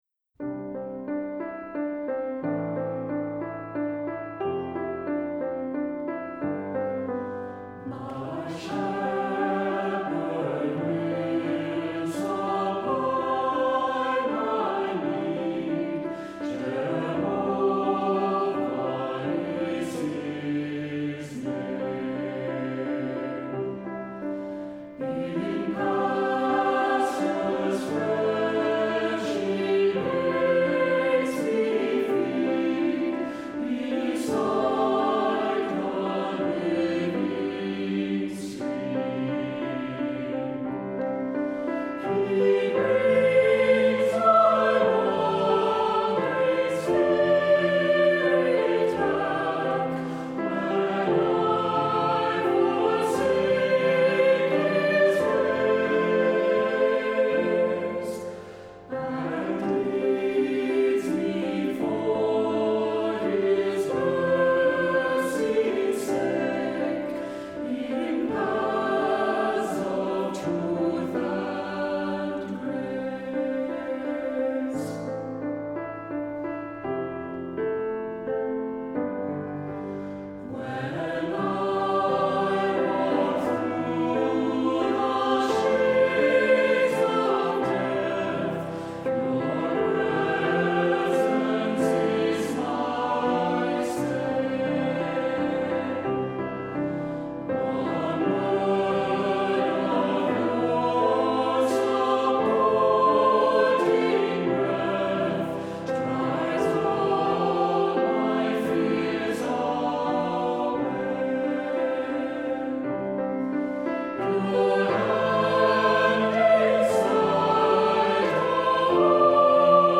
Voicing: Two-part mixed